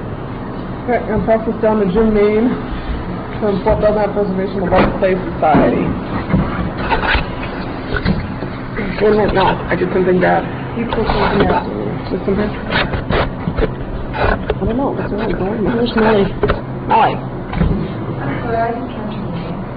lifeblood: bootlegs: 1997-10-02: honor the earth - missoula, montana (press conference)
04. press conference - winona laduke (0:19)